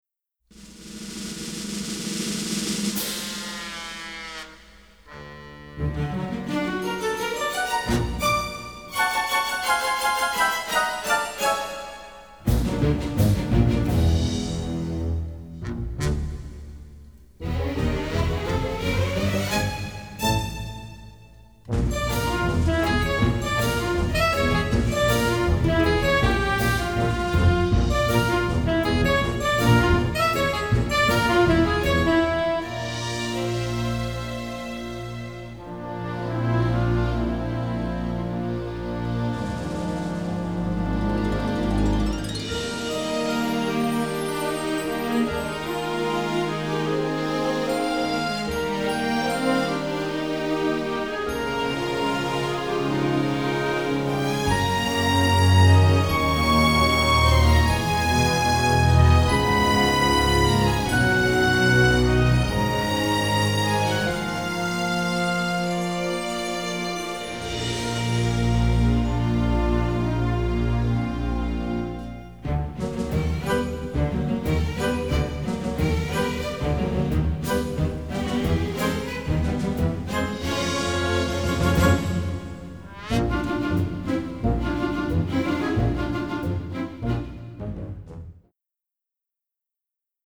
generous string section plus brass ensemble
strongly emotional finish to the score proper